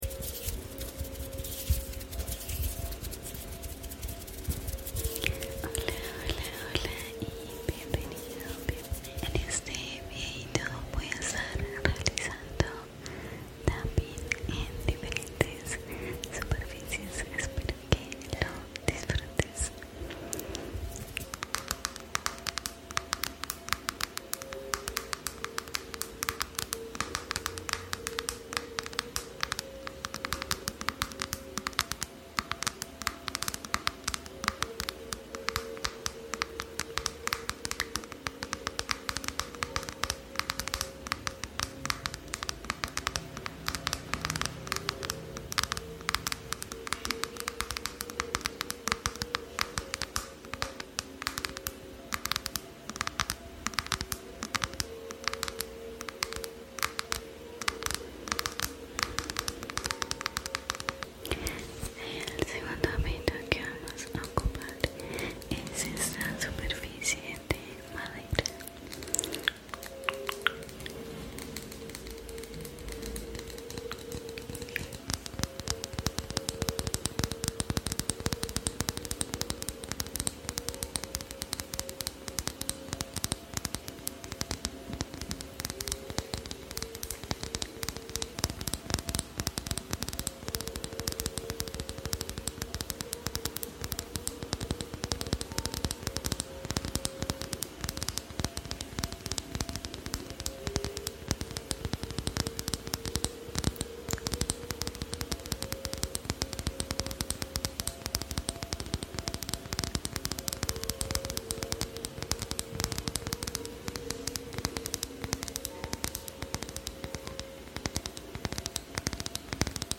Tapping 💫 ¿Rápido o lento? sound effects free download